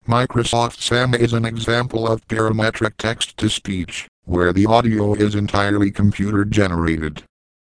Early voices like Microsoft Sam are examples of this parametric text to speech, where the audio is entirely computer generated, which is what gives it a more robotic quality.